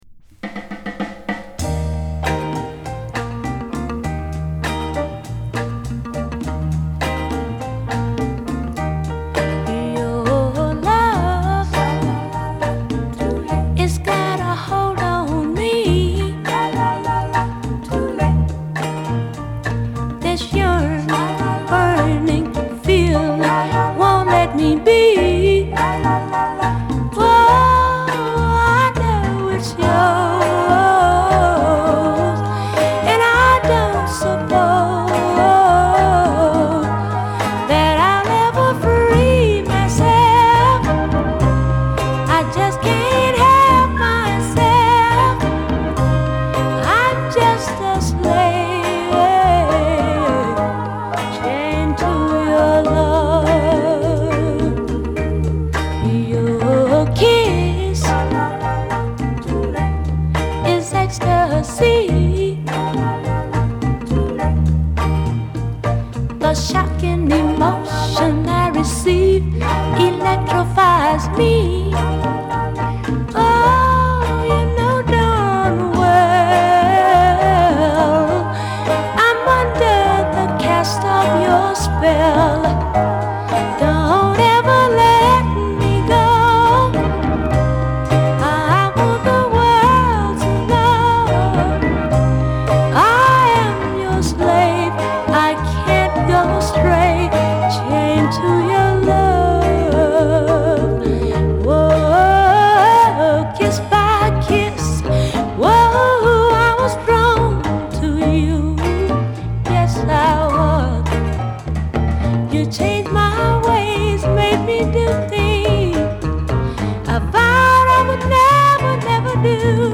ブルージーな気怠さを持った、スローで物哀しげなナンバー。
• R&B / BLUES / DOO WOP / BLACK ROCKER